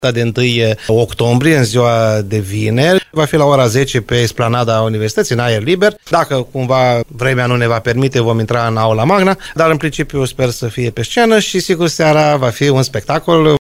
Rectorul VALENTIN POPA a declarat postului nostru de radio că evenimentele vor avea loc în aer liber.